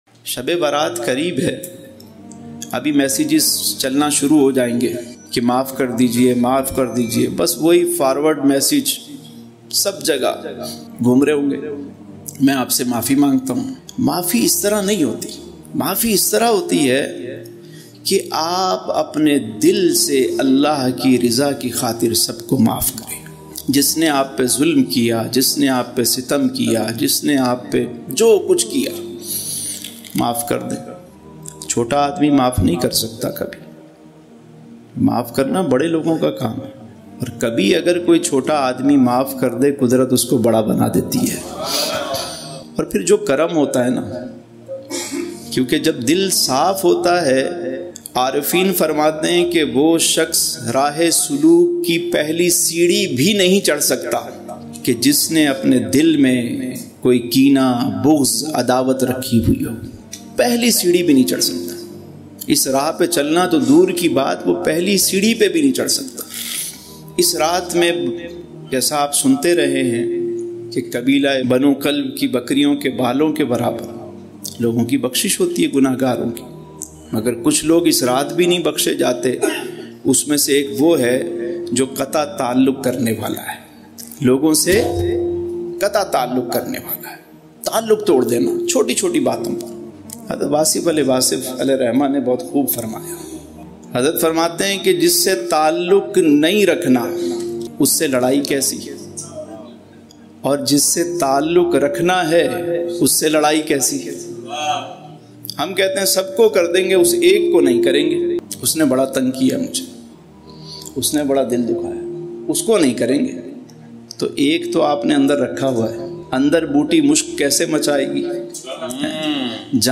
Emotional